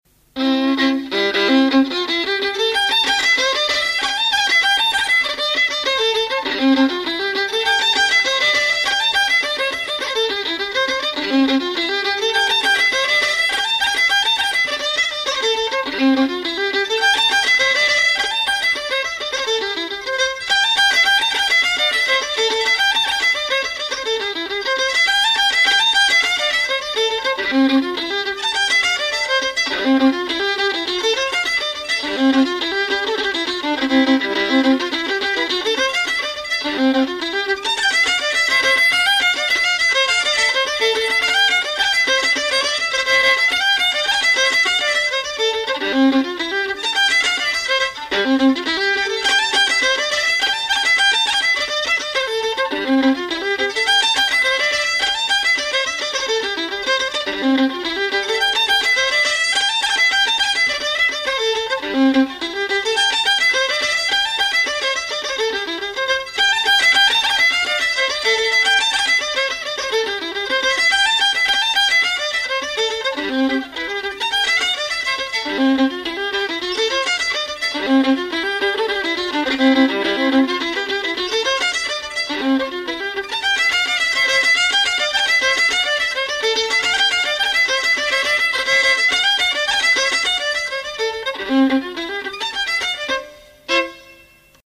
MP3s encoded from a 1988 cassette recording
C reel